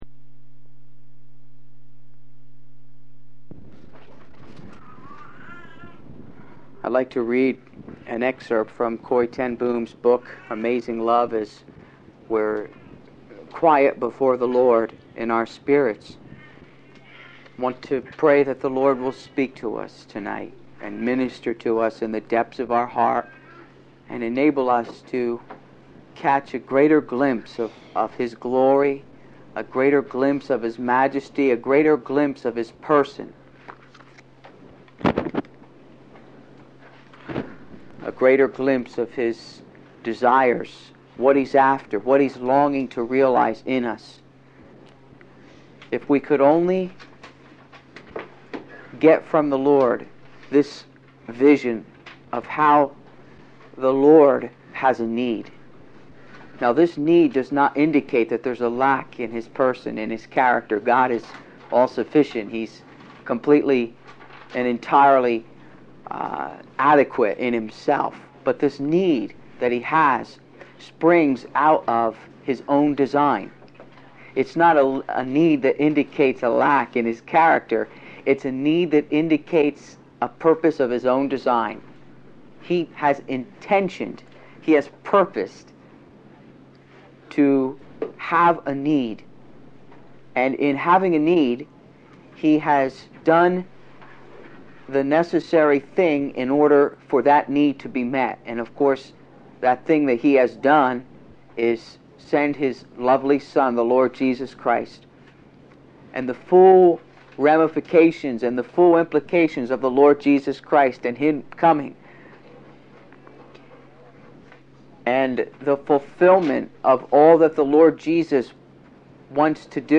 The sermon calls for a deep introspection regarding our prayer lives, challenging the tendency to approach God with personal agendas rather than seeking His will.